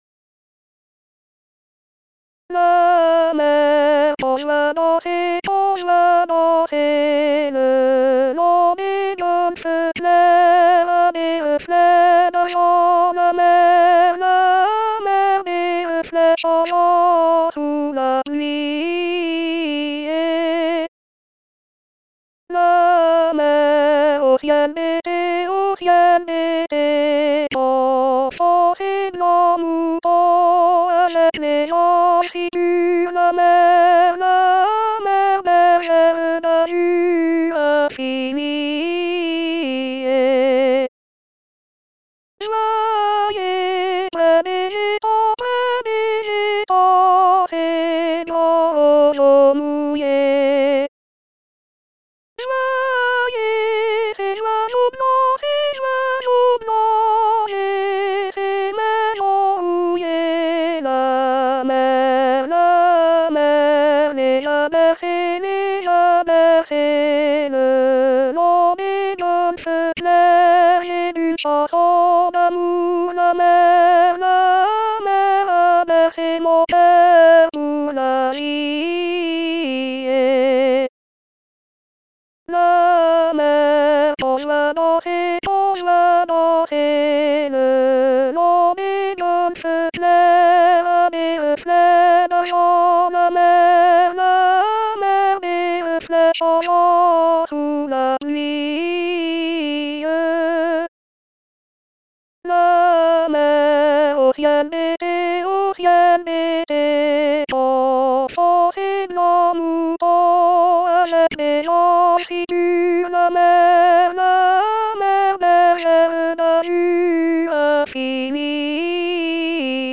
Mezzo seules